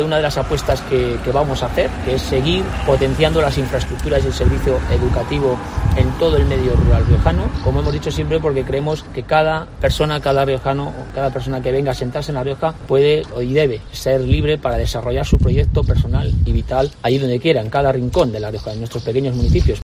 Capellán ha realizado estas afirmaciones junto al colegio de Medrano, durante una rueda de prensa en la que ha presentado las propuestas del Partido Popular en materia educativa